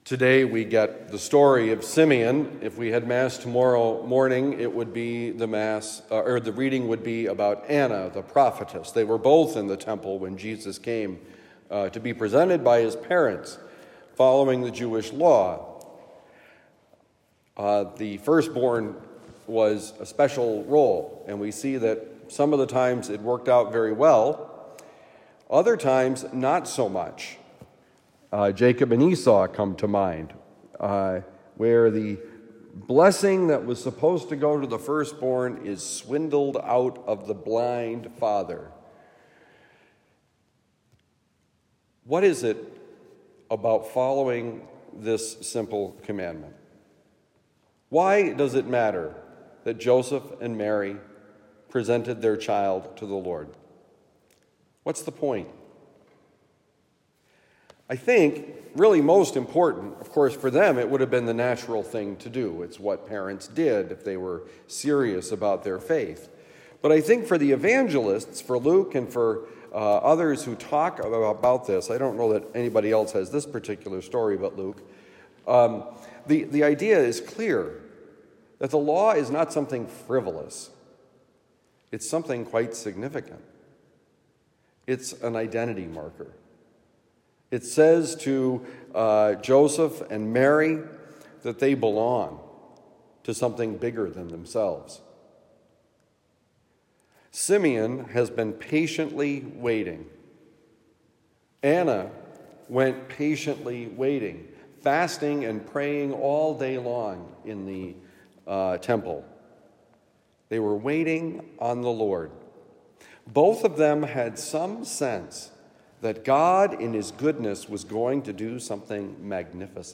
Something Bigger: Homily for Friday, December 29, 2023